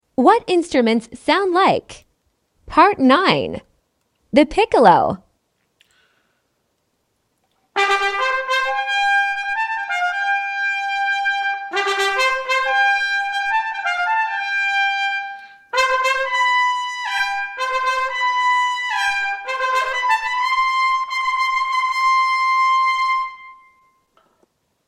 What Instruments Sound Like Part 9: The Piccolo